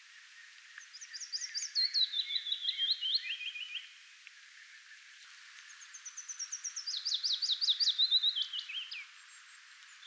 kaksi erilaista pajulinnun säettä
kaksi_erilaista_pajulinnun_saetta_en_tieda_ovatko_eri_linnuilta.mp3